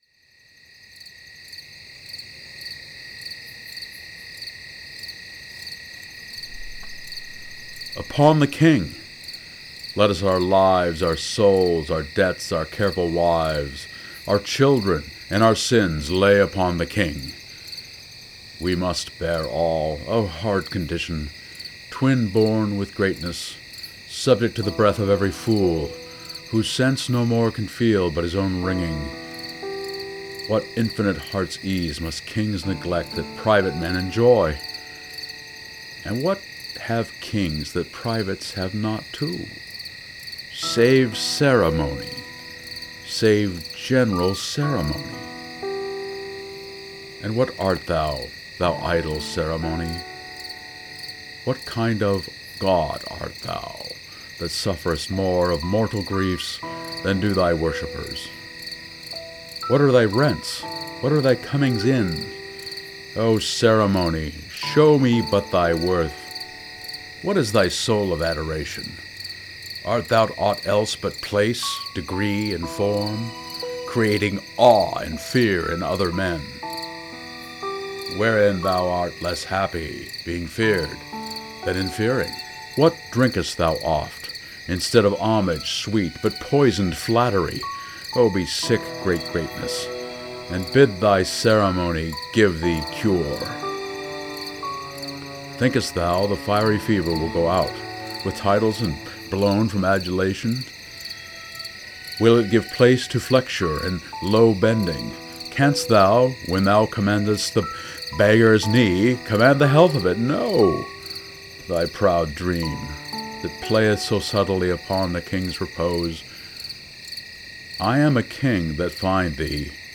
Here, in my take on King Henry’s lament as to his status as King, yet a man,  I lay in the sounds of night to emphasize the loneliness of leadership, the insomnia and thoughts the King alone must bear, while ordinary men are allowed sleep undisturbed by the cares of the sovereign.